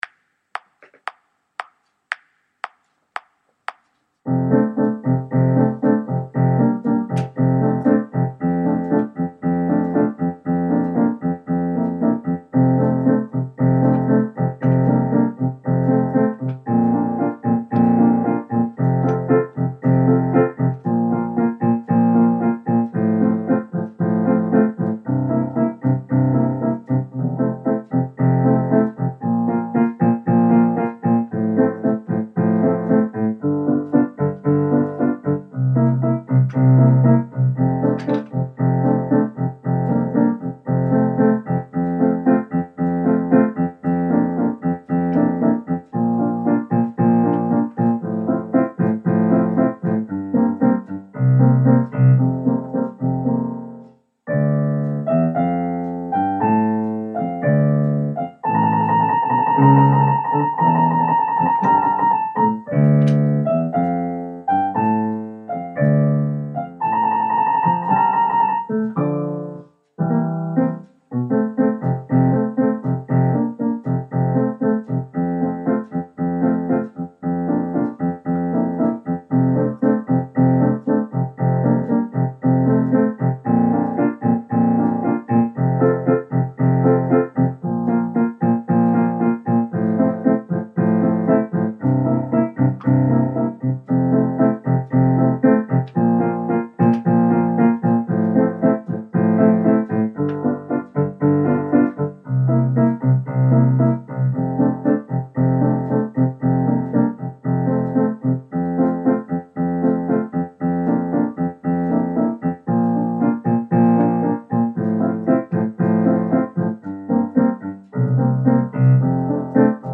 Branch Line Piano Part